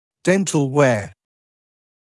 [‘dentl weə][‘dentl weə]стираемость зубов, стертость зубов